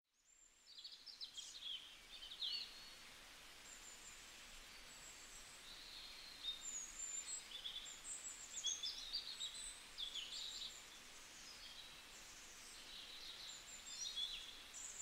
Sound Effects
1a Chirping Bird Sounds